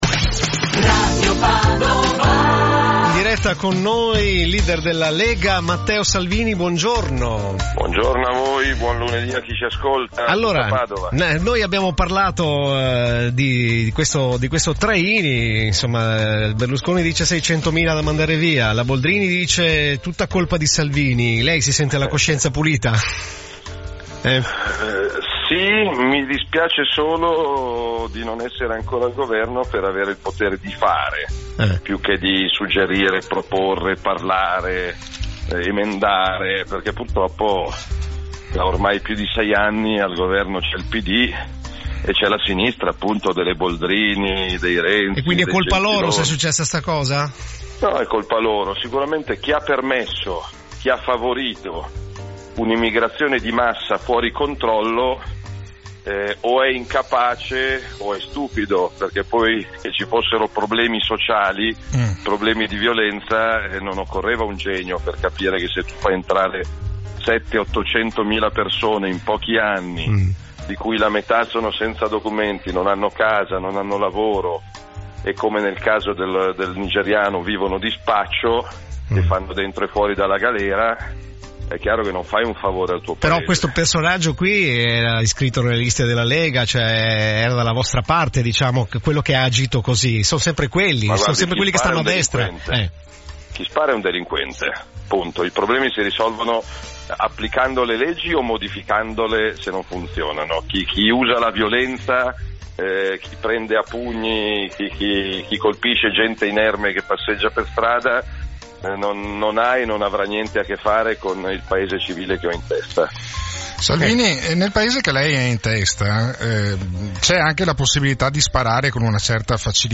Al link qui sotto la registrazione della telefonata di Matteo Salvini a Radio Padova